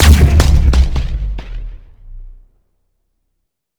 bounce.wav